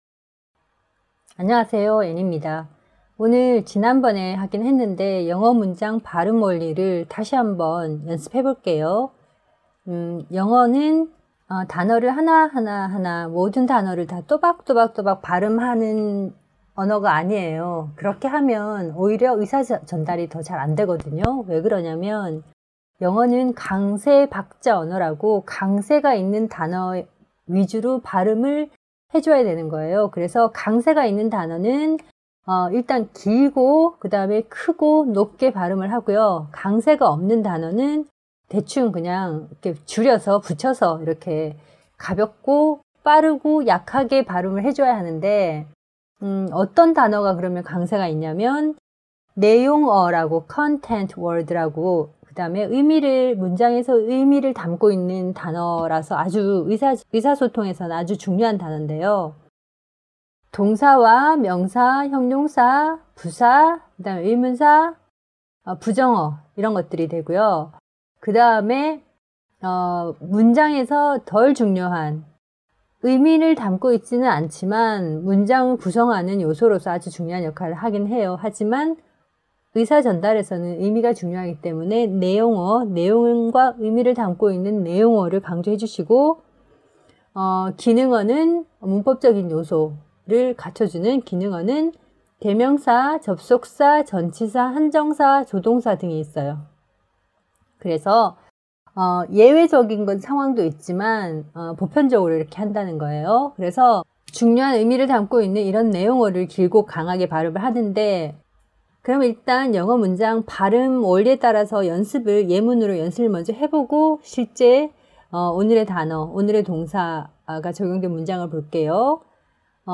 샘플강의-영어문장발음원리(전반부).mp3